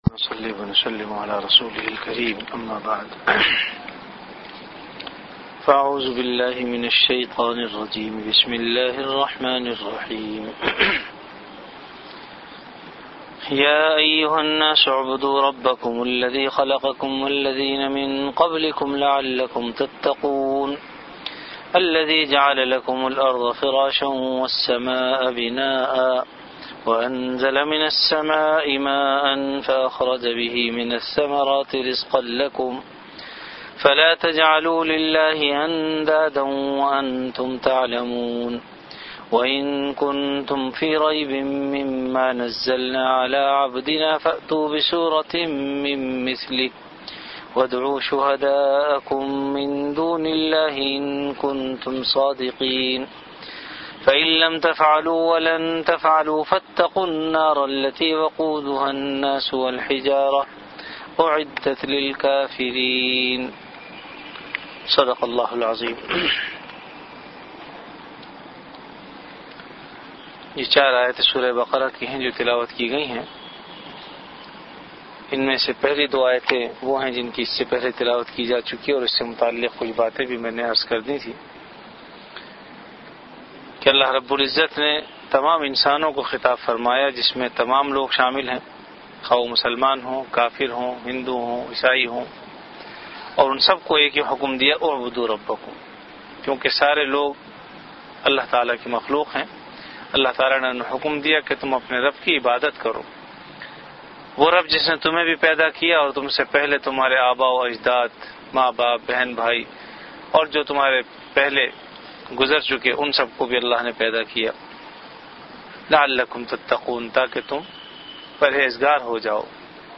Dars-e-quran · Jamia Masjid Bait-ul-Mukkaram, Karachi